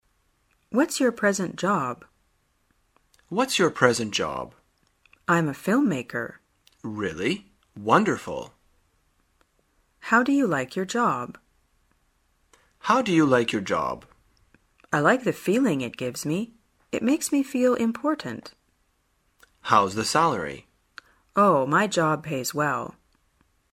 旅游口语情景对话 第320天:如何谈论工作